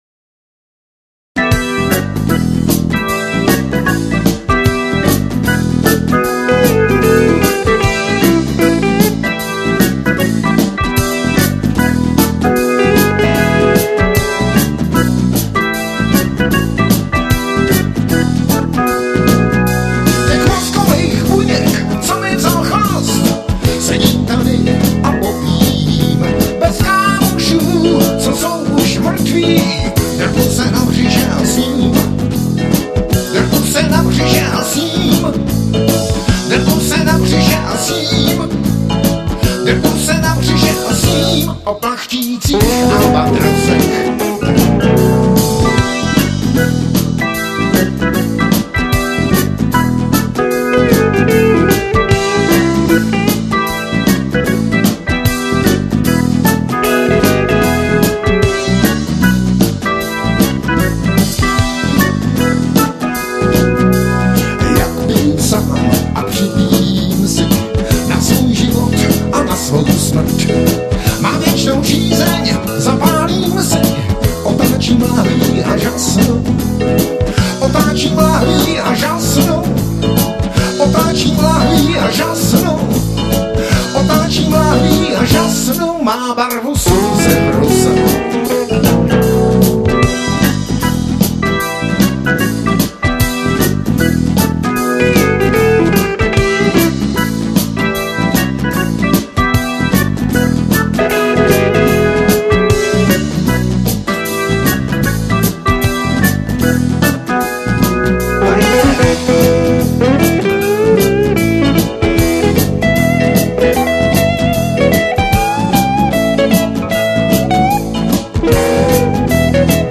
POHODA rock
Bicí
Klávesy
Sólova kytara